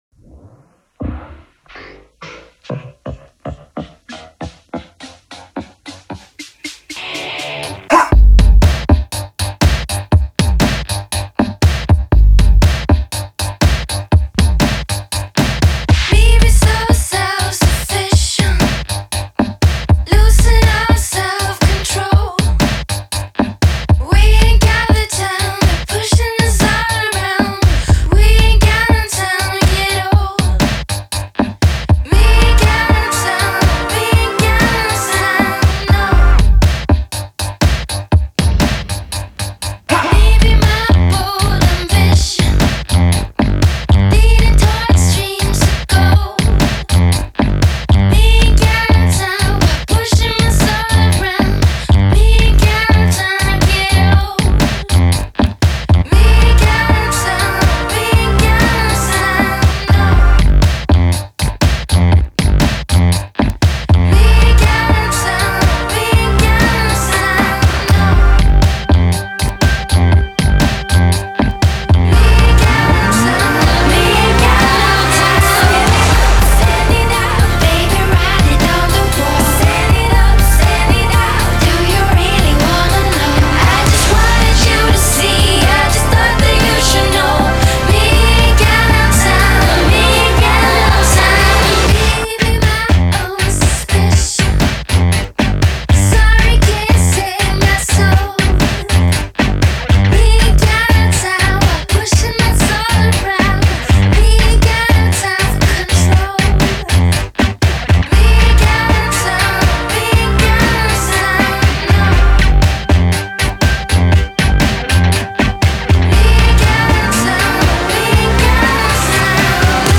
Genre: Pop, Electro-Pop